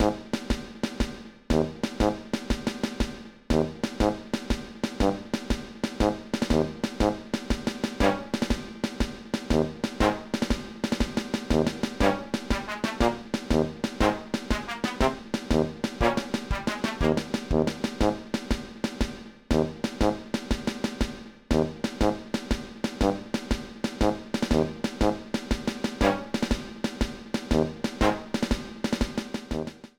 Level preview music